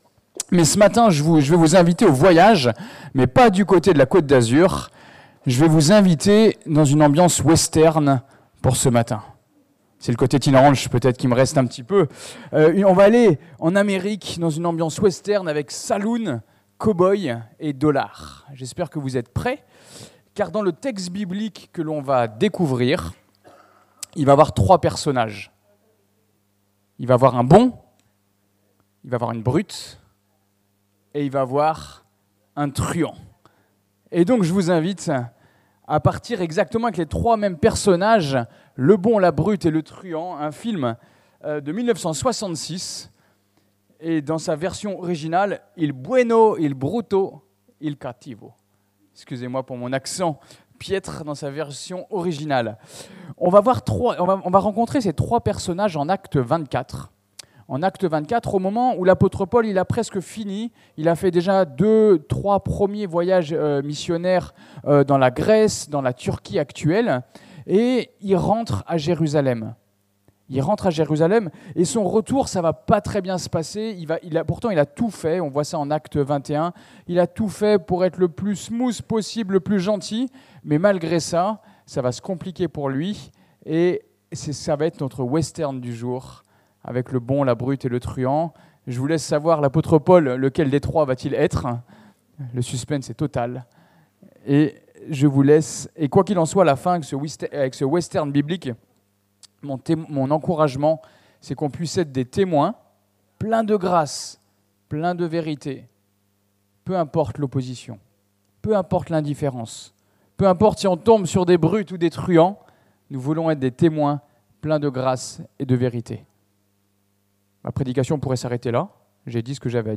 Le bon, la brute et le truand - Prédication de l'Eglise Protestante Evangélique de Crest sur le livre des Actes des apôtres
Actes Prédication textuelle